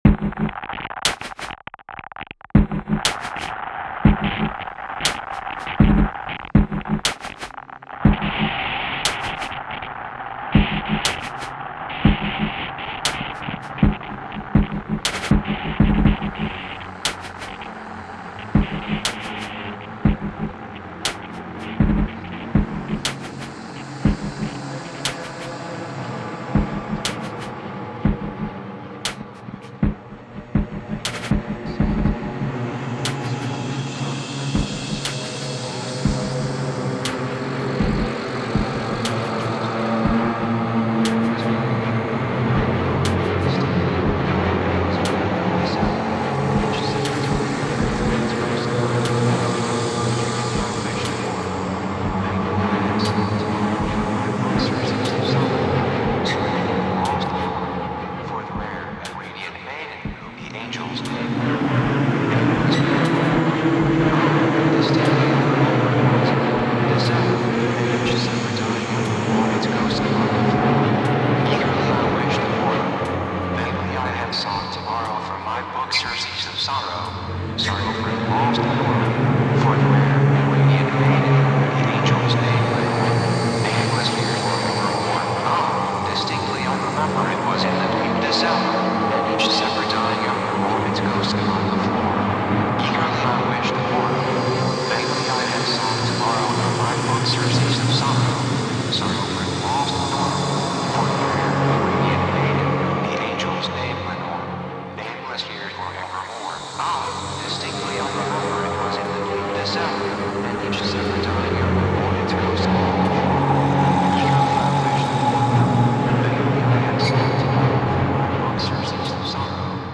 本作は大編成のストリングスと男性合唱からなるサイバー・ゴシックなオーケストレーションと、
デジタル・ハードコアなビートとのアマルガムである。
ヒトに非ざる非実存ナレーターは、MacOS X Leopardによる合成言語。
漆黒のサイバー・ハードコア・ゴシックが鳴る。